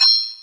Gamer World Perc 4.wav